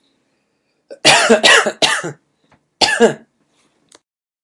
地铁人声咳嗽声
描述：地铁，人声，咳嗽声
标签： 地铁 人声 咳嗽声
声道立体声